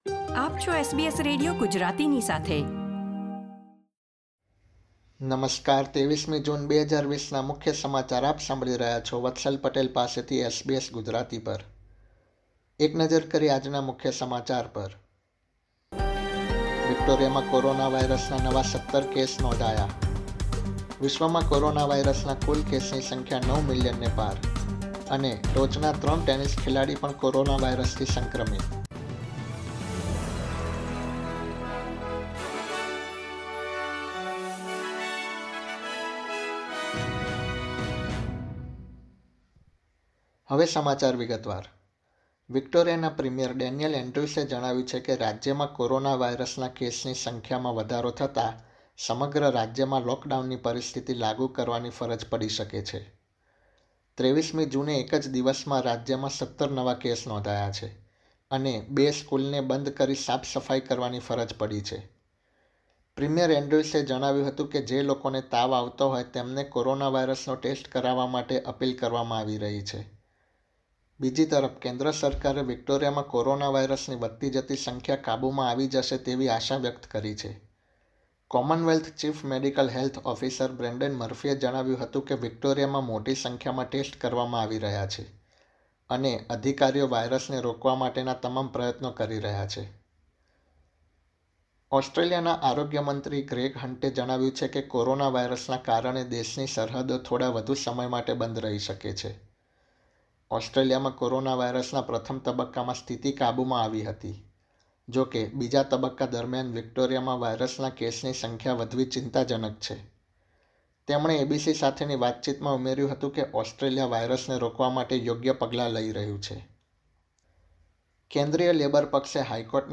SBS Gujarati News Bulletin 23 June 2020